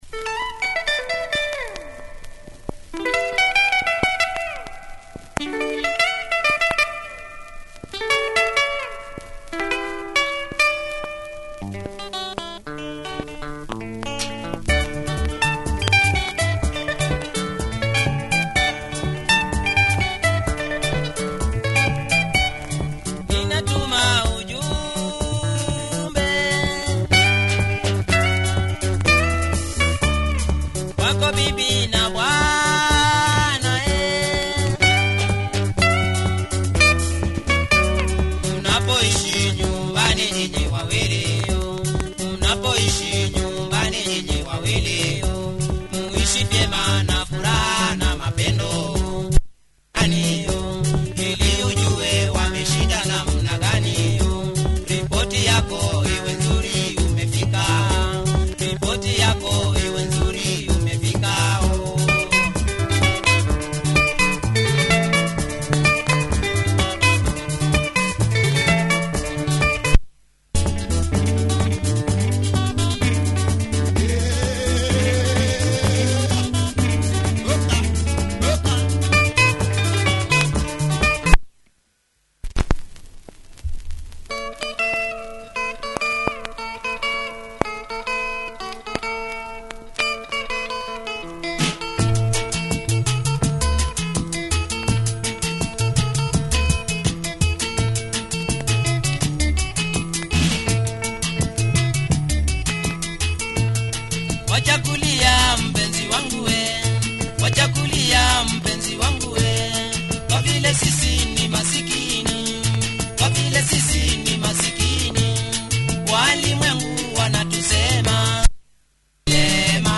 Fine kamba benga here by this legendary outfit
Disc is pretty clean.